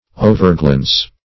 Overglance \O`ver*glance"\, v. t. To glance over.